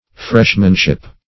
Freshmanship \Fresh"man*ship\, n. The state of being a freshman.